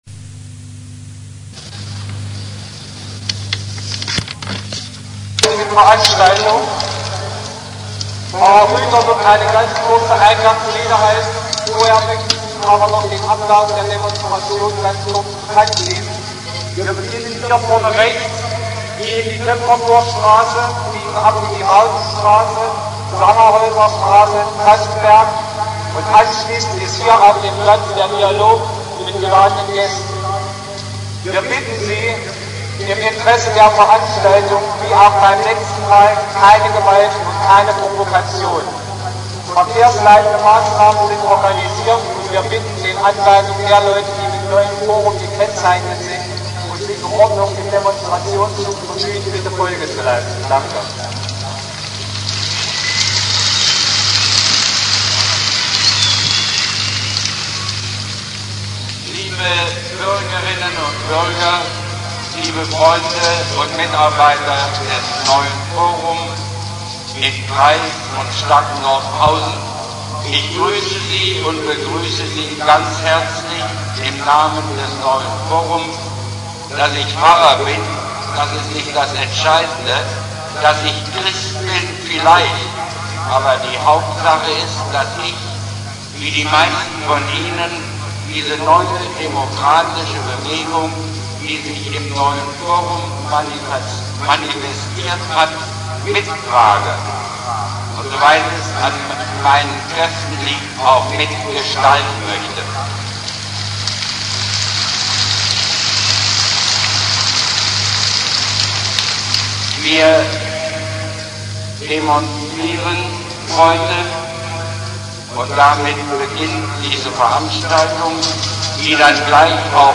10.11.2014, 08:50 Uhr : Der 7. November 1989 - der August-Bebel-Platz in Nordhausen ist mit Menschen "vollgestopft". Sie sind einem Aufruf des Neuen Forum gefolgt und wollen für Demokratie, Bürgerrechte und gegen die SED-Vorherrschaft demonstrieren.
Erster Teil der Dienstagsdemo am 7. November 1989
Des Weiteren werden die unterschiedlichsten Frauen und Männer auf dem "Podium" recht unterschiedlich durch die Tausenden begrüßt.